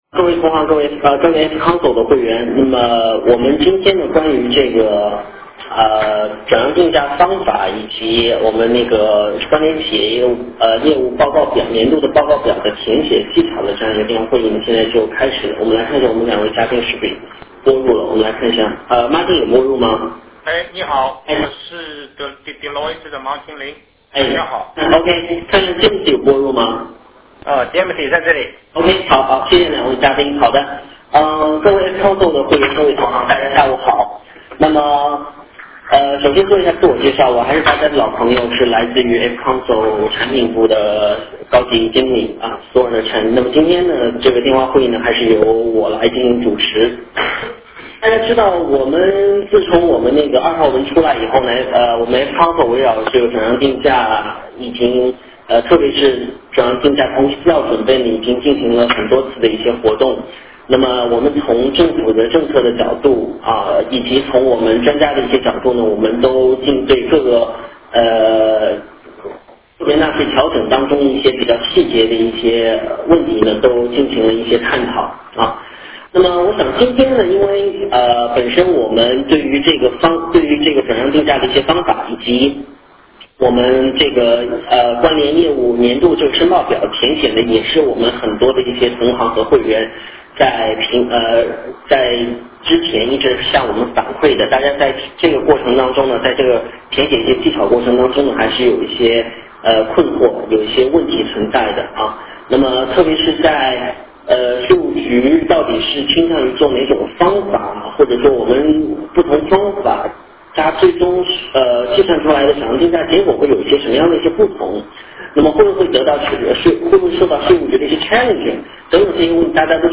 活动形式： 电话会议